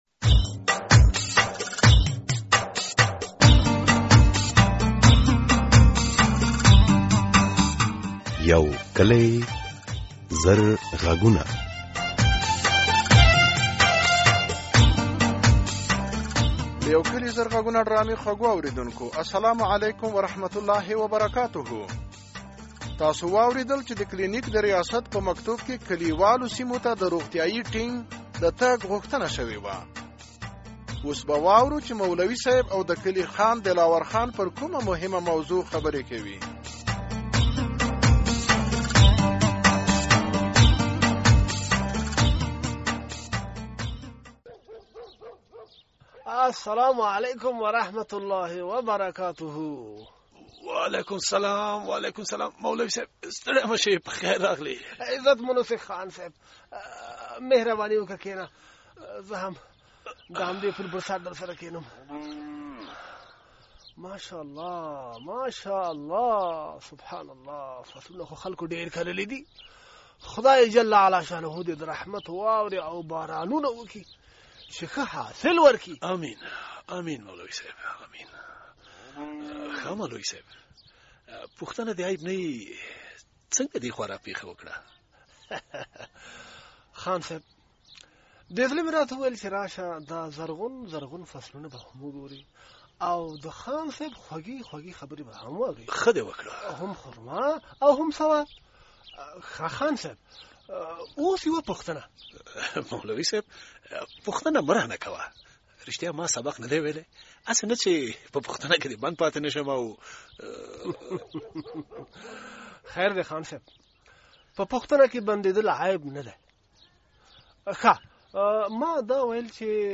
په کلیو او لیرې پرتو سیمو کې د روغتیايي مرکزونو فعالیت څه ګټې لري؟ د یو کلي زر غږونو ډرامې په دې برخه کې ...